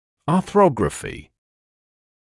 [ɑː’θrɔgrəfɪ][аː’срогрэфи]артрография